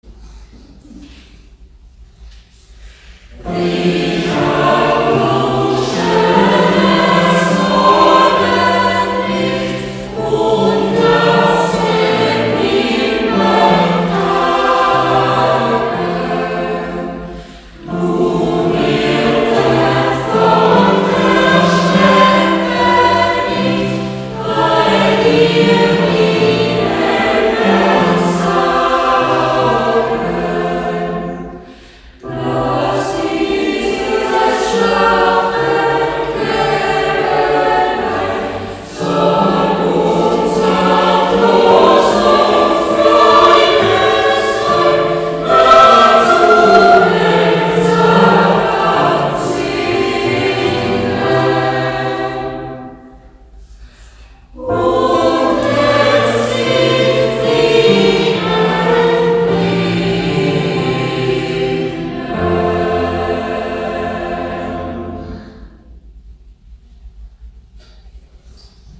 In insgesamt 9 Proben üben wir bekannte, aber auch weniger bekannte, mehrstimmige Weihnachtslieder ein. Am Weihnachtsgottesdienst vom 25.12.2025 gestaltet der Chor mit den Liedern den musikalischen Teil des Festgottesdienstes.